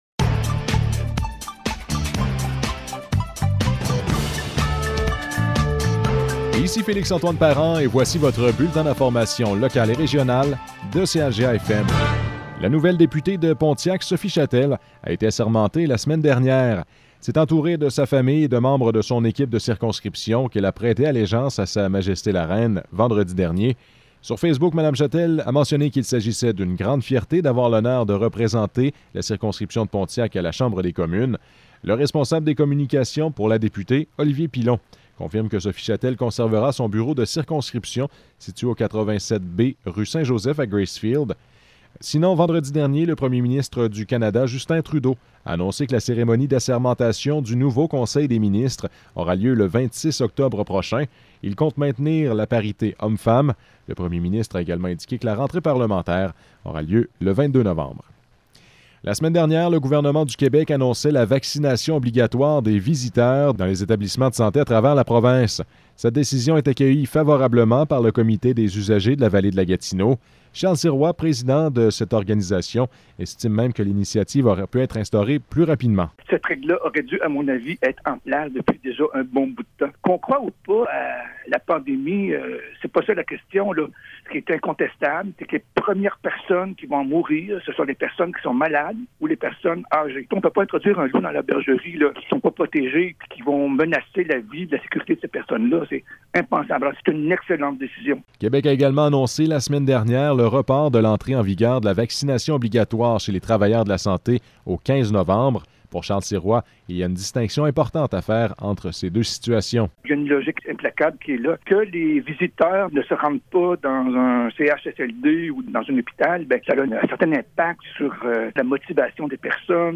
Nouvelles locales - 18 octobre 2021 - 12 h